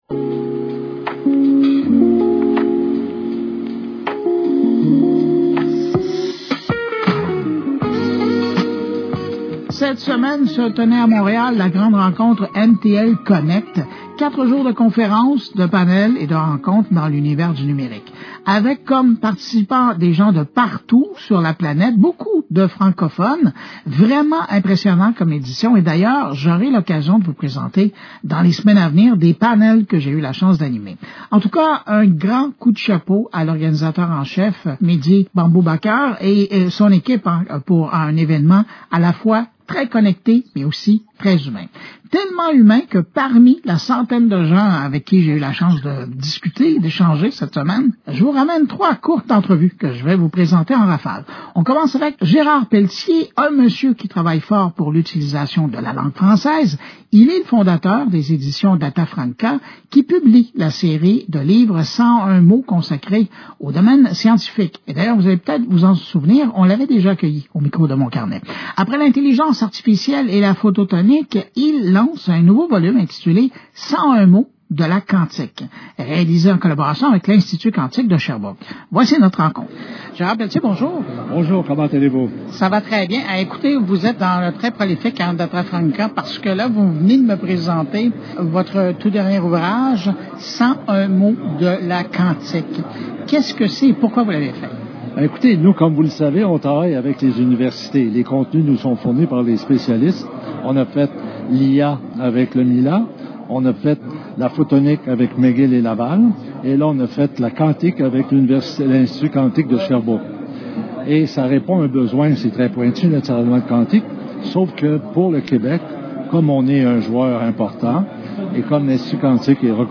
lors de l’événement MTL CONNECT